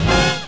JAZZ STAB 17.wav